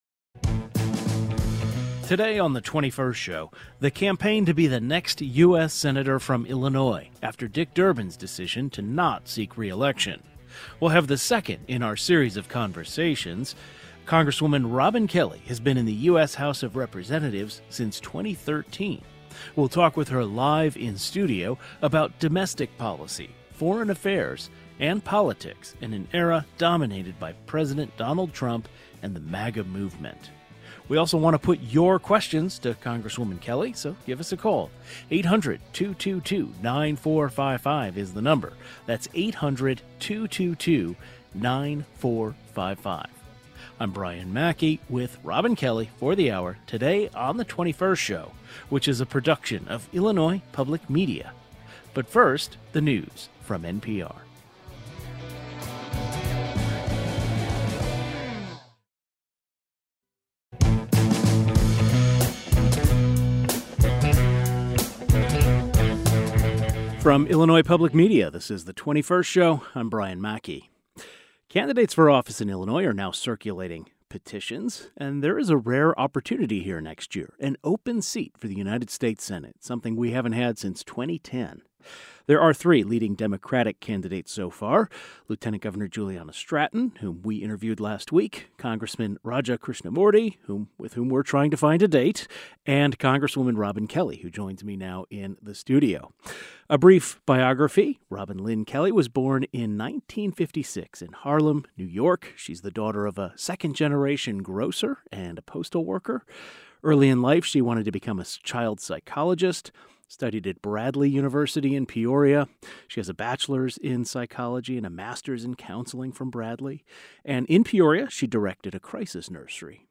We continue our series of in-depth conversations with candidates for U.S. Senate, who are vying for Senator Dick Durbin's seat. Congresswoman Robin joins us live in our studio in Urbana. The 21st Show is Illinois' statewide weekday public radio talk show, connecting Illinois and bringing you the news, culture, and stories that matter to the 21st state.